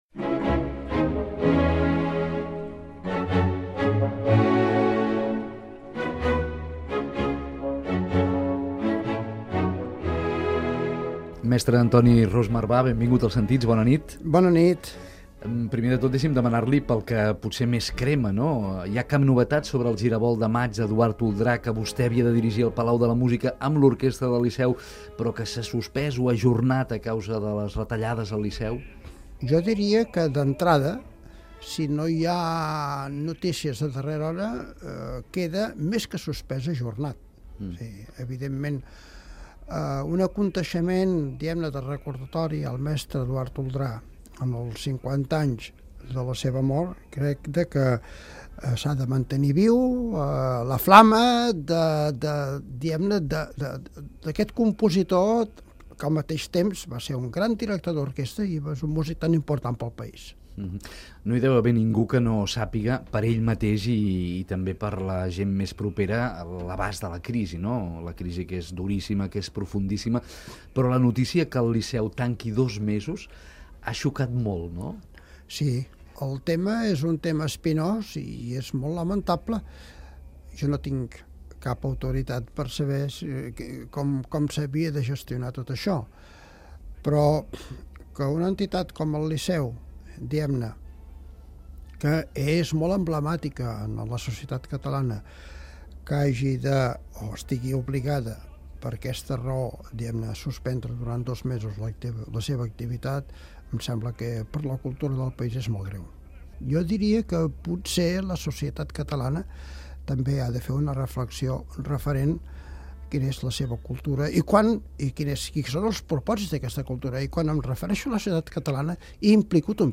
Aquí teniu l’inici de l’entrevista, que és el que fa referència al Liceu, la resta ja us deixaré l’enllaç per escoltar-la íntegra.